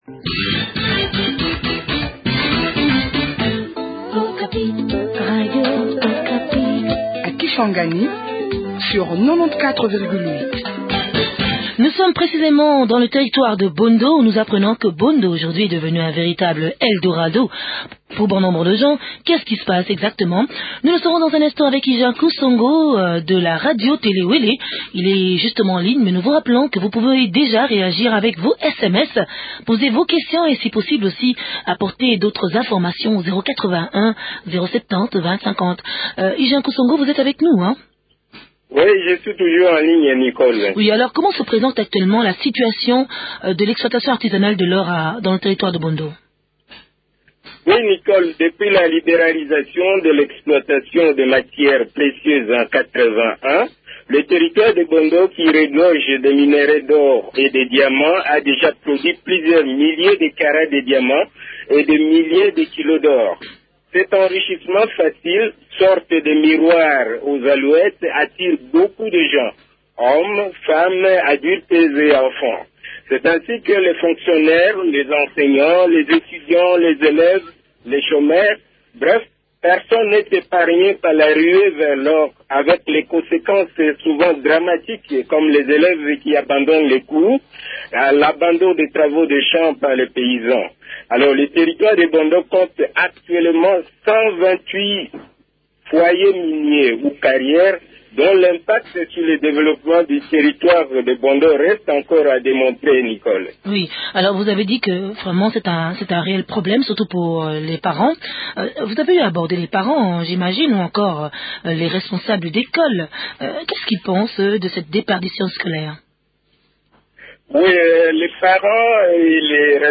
recoivent Lufulwabo Crispin, administrateur de territoire de Bondo.